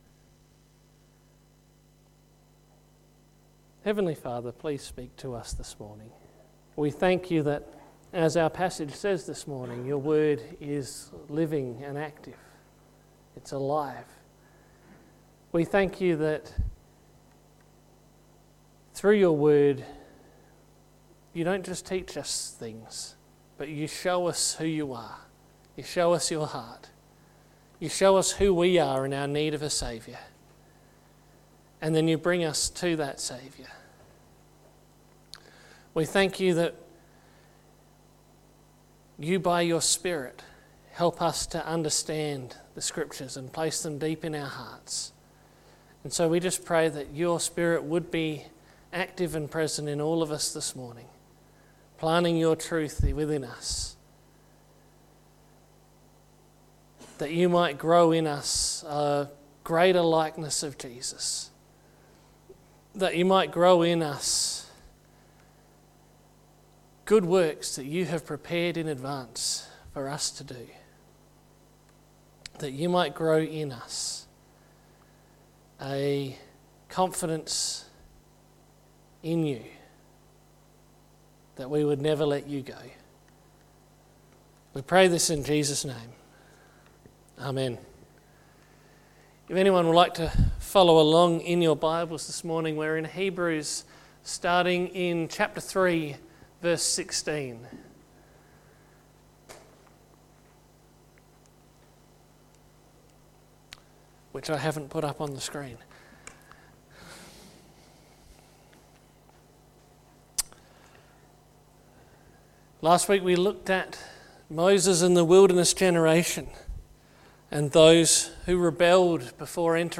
Sermons by Birdwood United Church